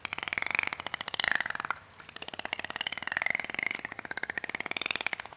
جلوه های صوتی
دانلود صدای دلفین 10 از ساعد نیوز با لینک مستقیم و کیفیت بالا